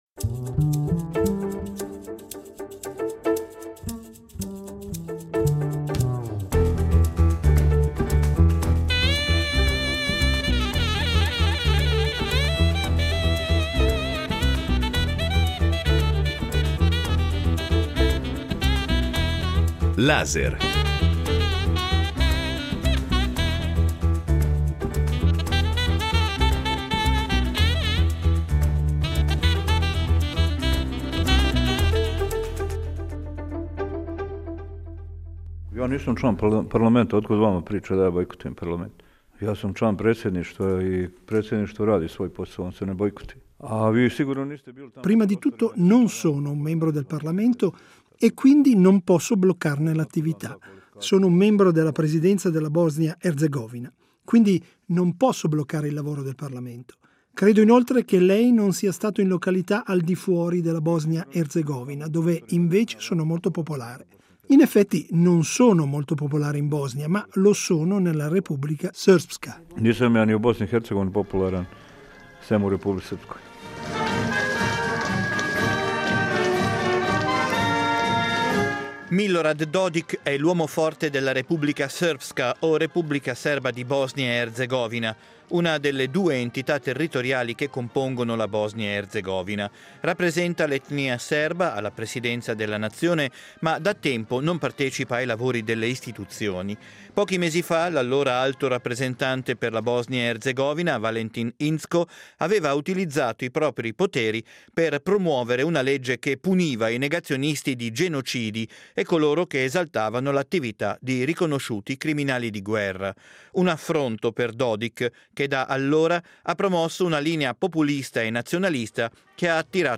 Affermazione, questa, ripetuta in esclusiva per "Laser".